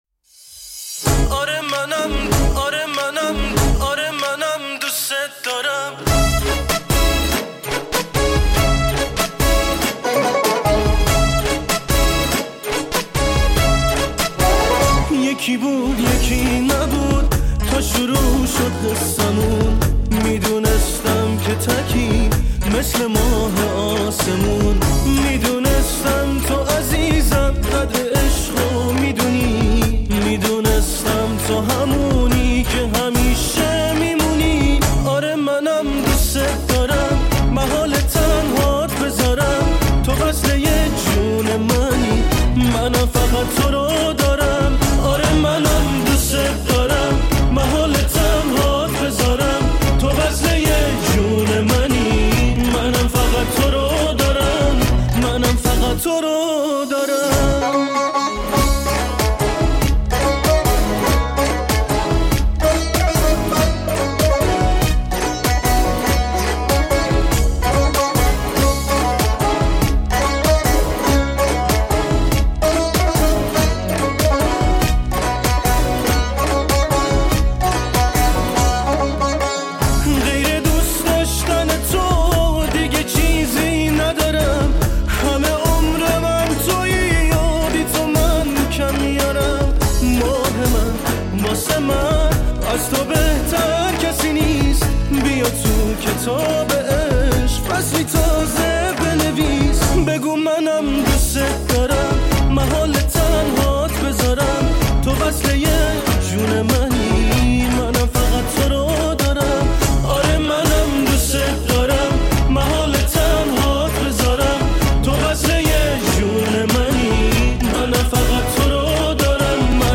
Live In Concert